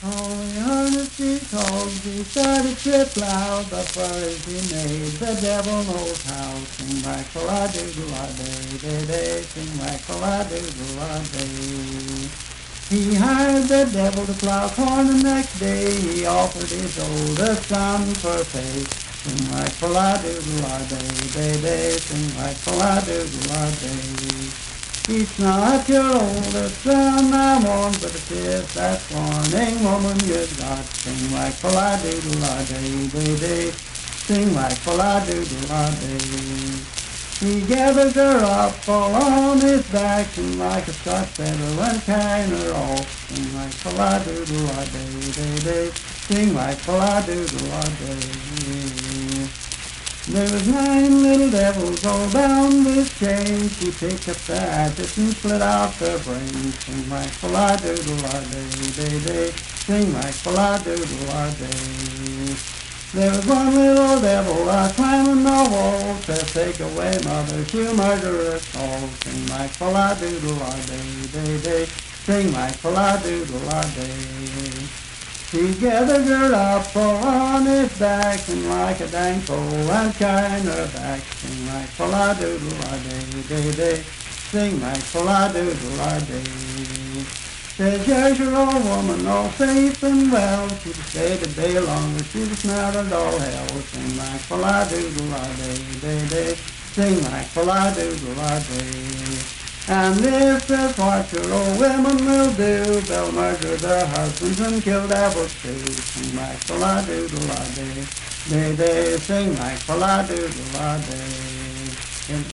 Unaccompanied vocal music
Verse-refrain 9(4w/R).
Performed in Dryfork, Randolph County, WV.
Voice (sung)